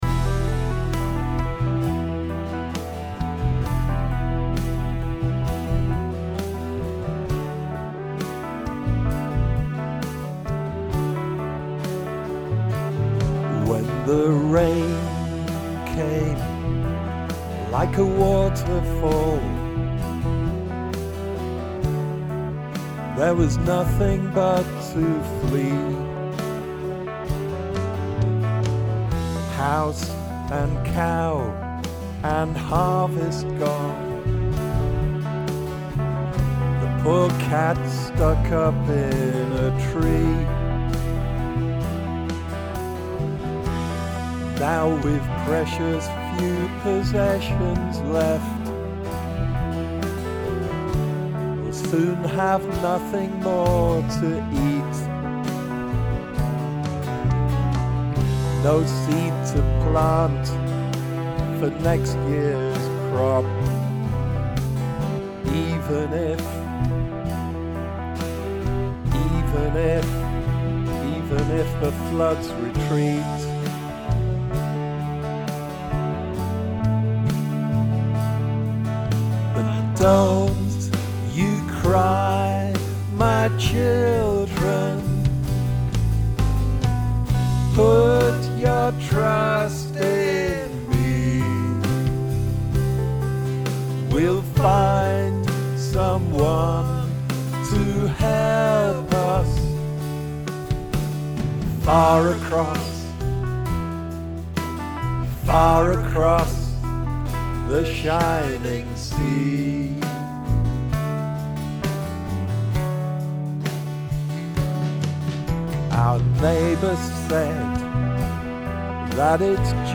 New mix uploaded 1 March 2026 with new piano part.